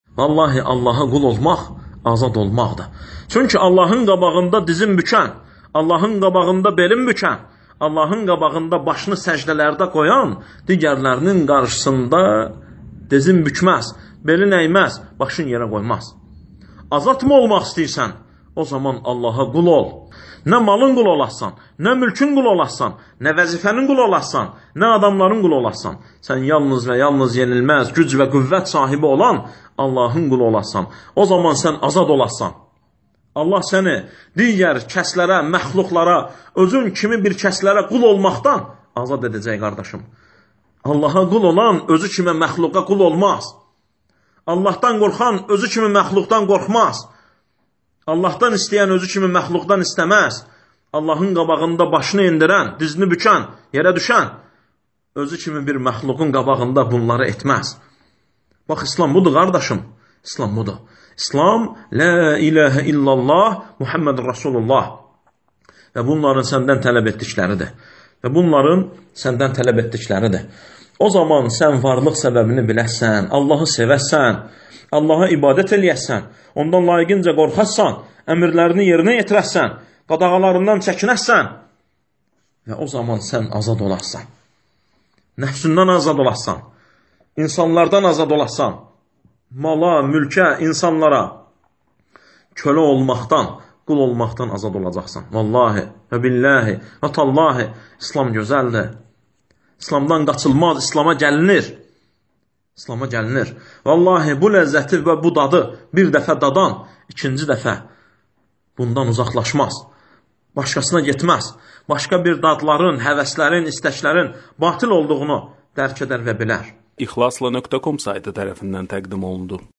Dərslərdən alıntılar – 100 parça